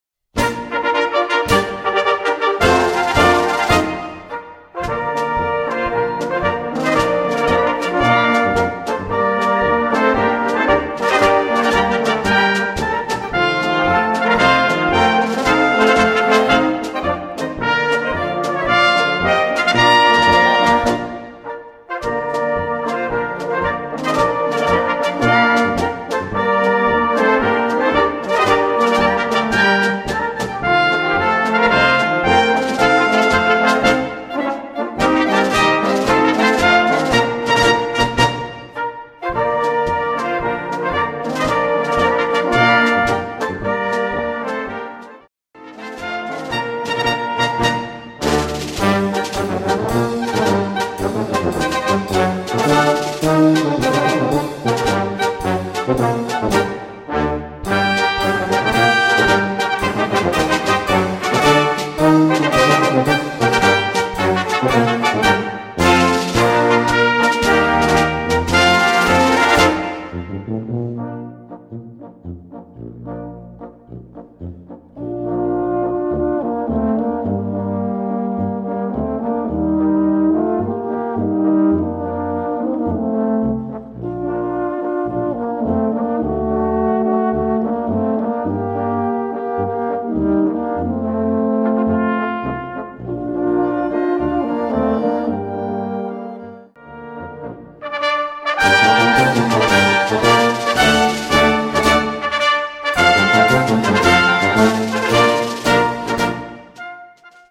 Gattung: Konzertmarsch
Besetzung: Blasorchester
Ein toller Konzertmarsch für Blasorchester